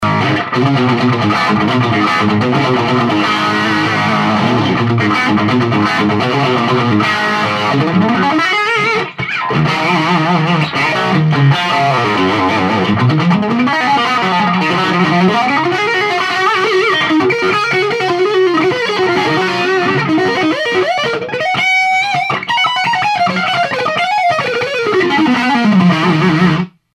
A strat,and used my PC sound card to record with,just a SM 58.